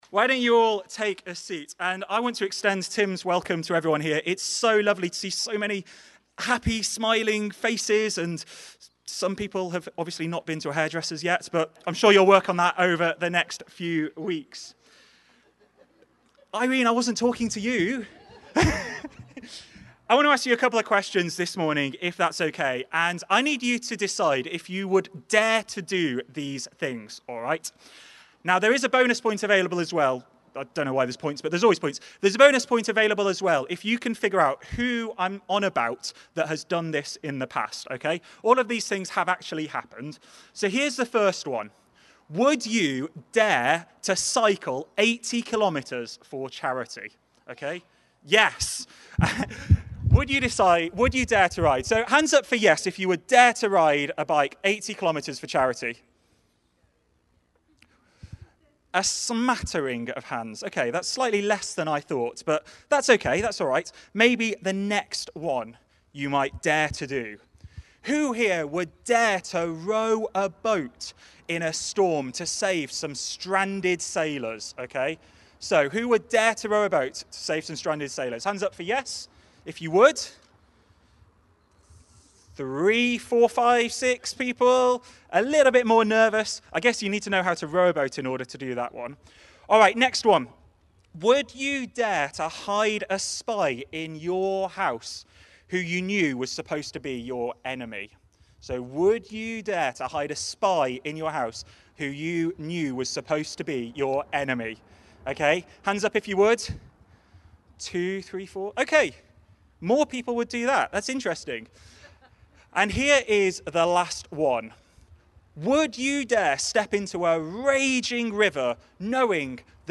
Watch Listen play pause mute unmute Download MP3 Thanks for joining us this morning live from our car park as we come together both online and in-person to worship! We're continuing our series today looking at some of the lessons we can learn from the life of Joshua.